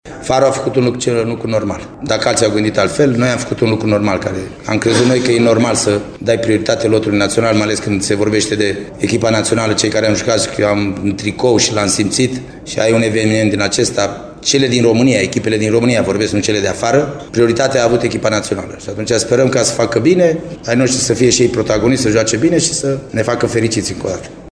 Farul Constanța dă cei mai mulți jucători la lot, cinci, iar antrenorul ”marinarilor”, Gheorghe Hagi, a declarat la finalul meciului de campionat de la Arad, că această atitudine este una logică: